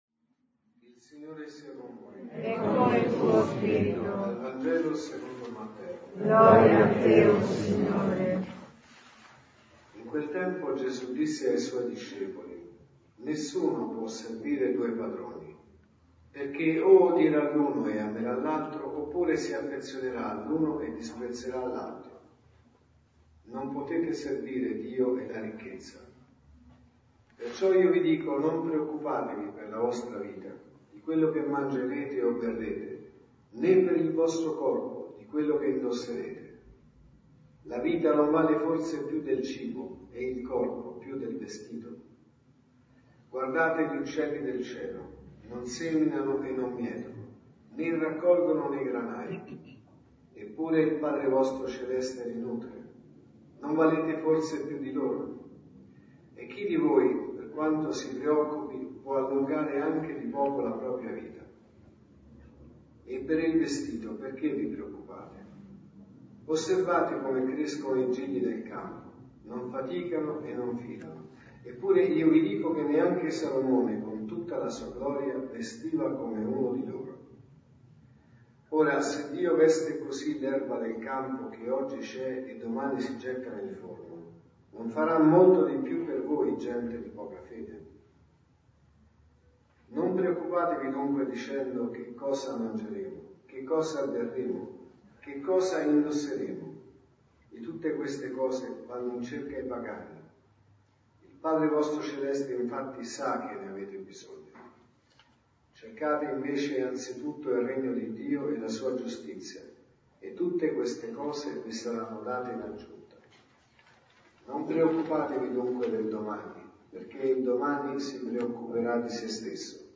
Omelie Messa della mattina